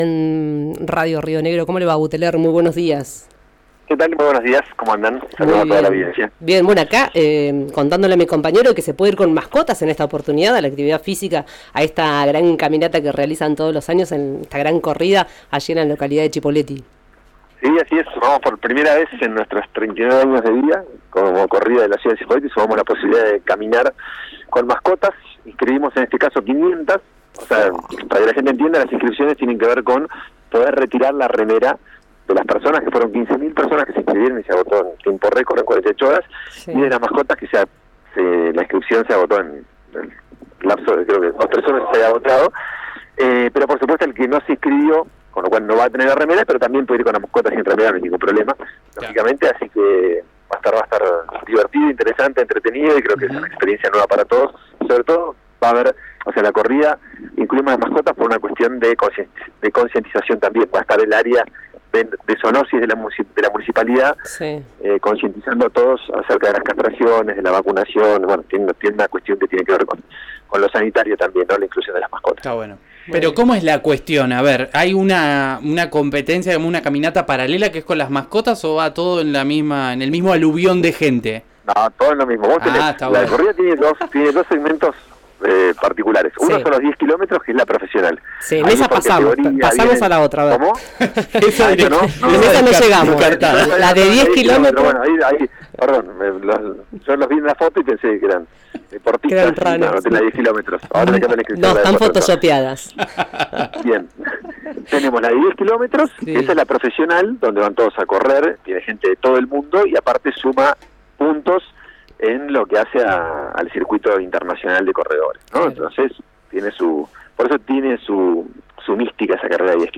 Escuchá a Rodrigo Buteler en RÍO NEGRO RADIO:
Una vez que se concrete ese paso, veremos quién hará la obra«, expresó el jefe comunal ante los micrófonos de RÍO NEGRO RADIO.